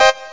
Amiga 8-bit Sampled Voice
SawWave37.mp3